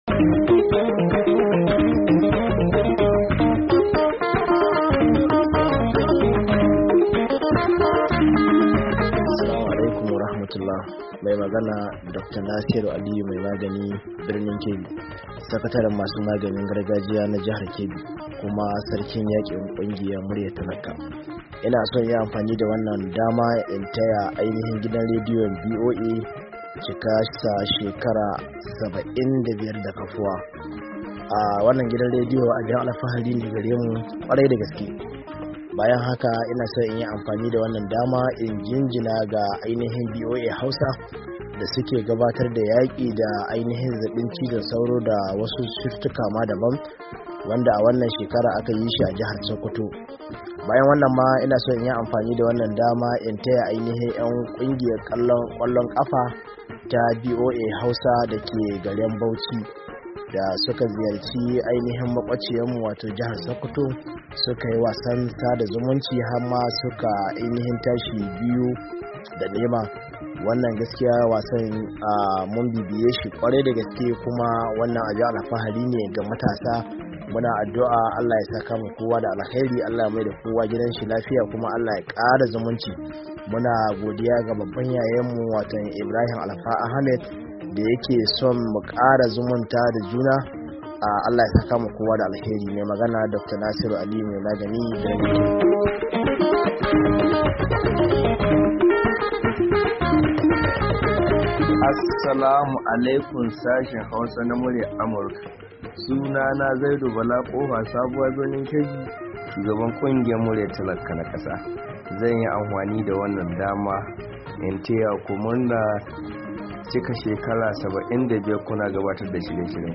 Shirin Sakon Muryoyi Ta WhatsApp Na Masu Sauraren DandalinVOA